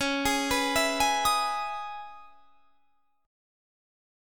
C#m9 chord